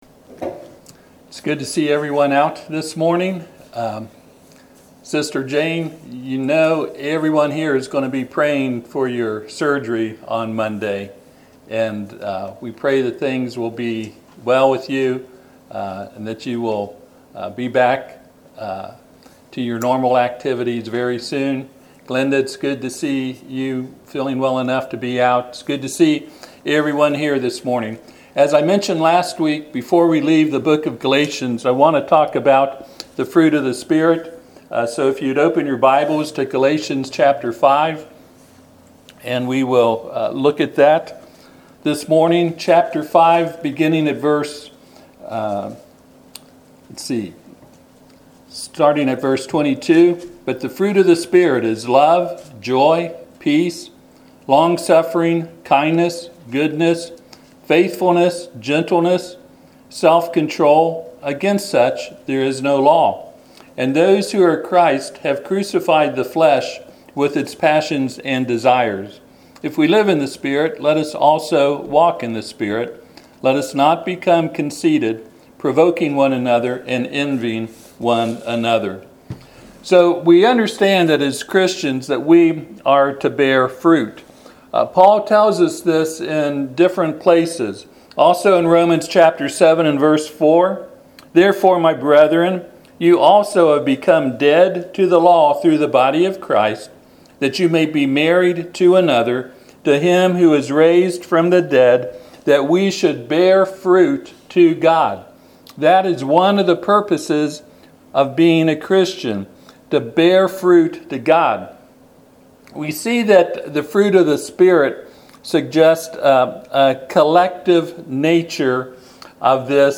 Passage: Galatians 5:22-26 Service Type: Sunday AM